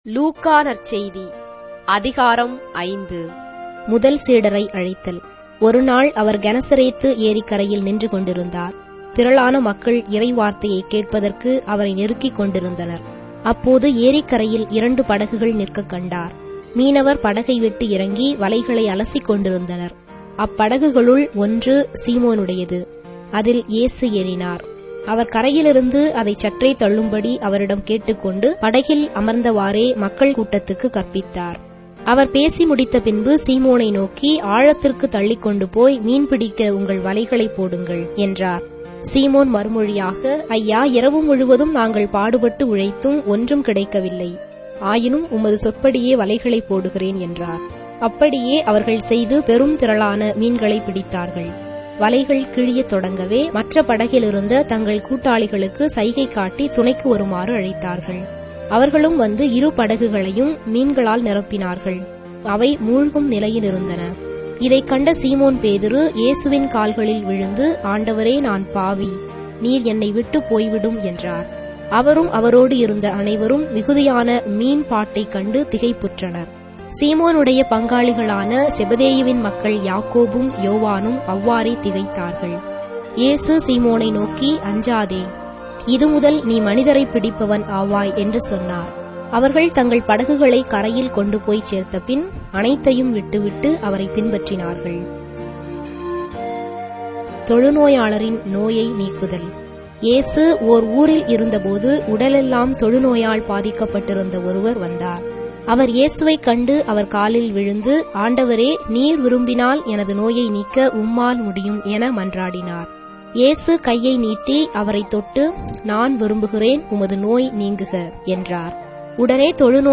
Tamil Audio Bible - Luke 2 in Ecta bible version